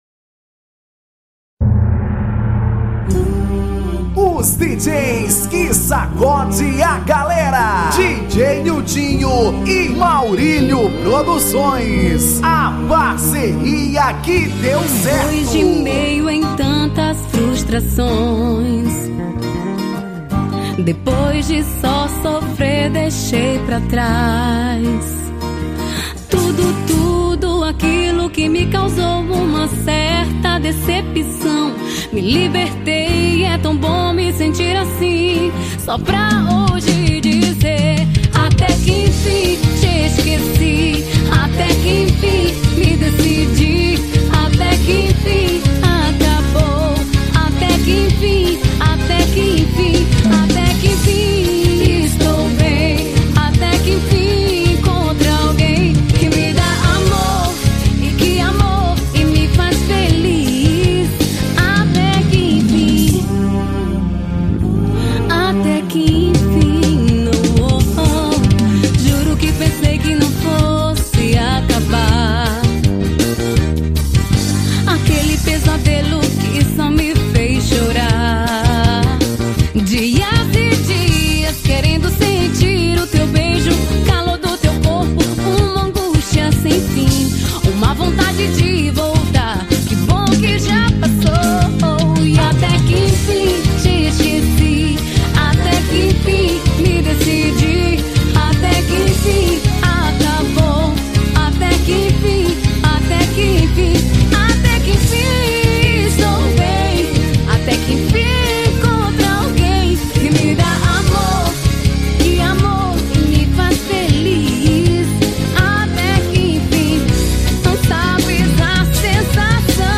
Forró Romantico 2014